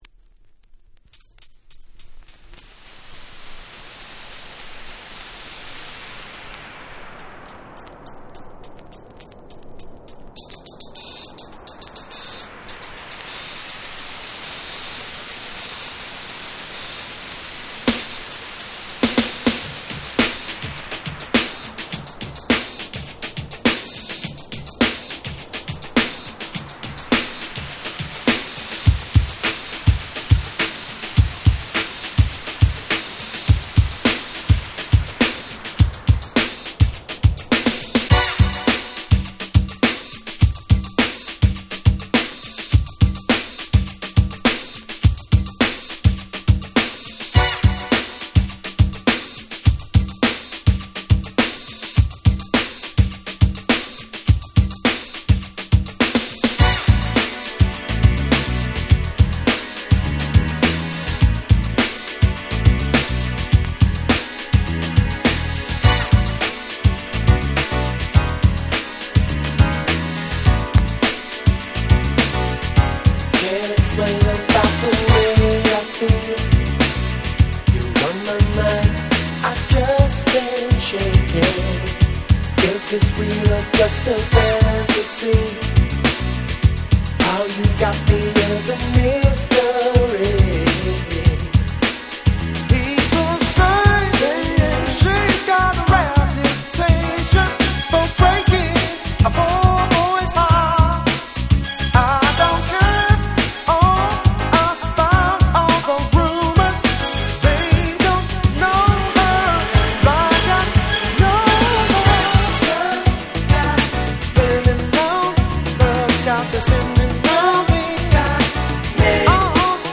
90' Nice UK Street Soul / Ground Beat !!